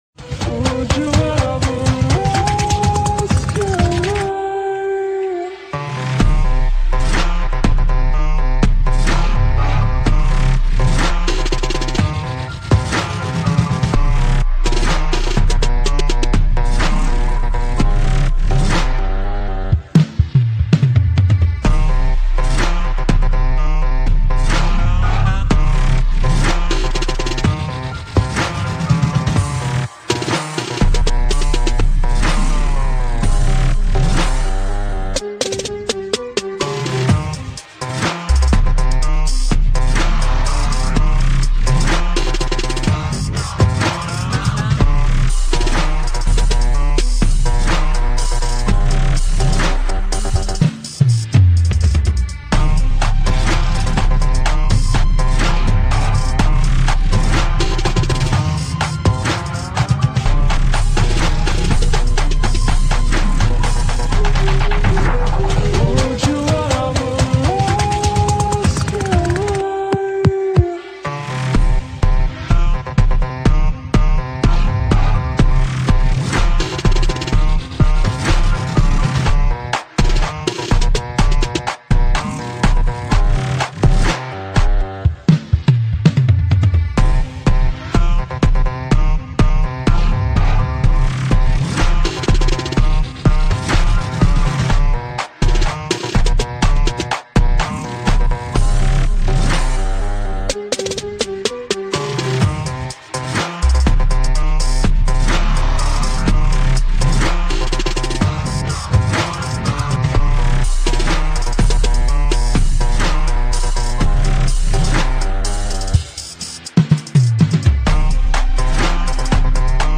(slow)